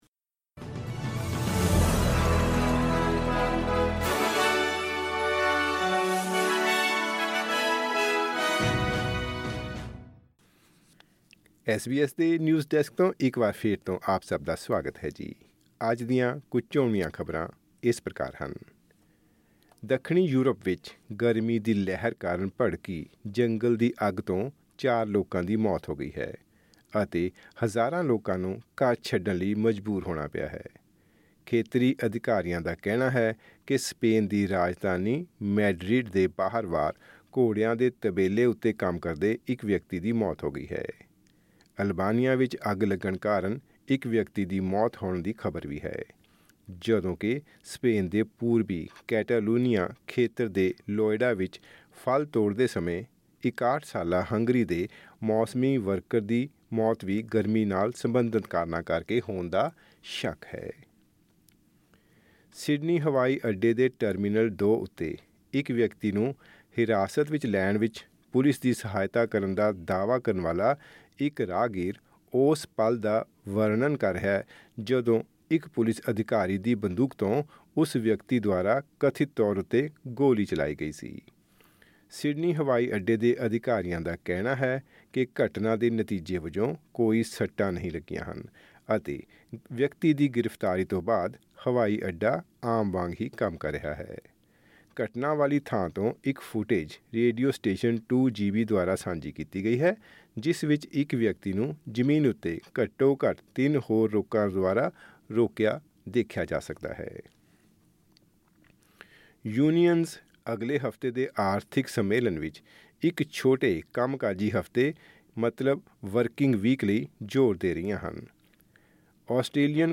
Newsflash: Australian Council of Trade Unions recommends four-day workweek in some areas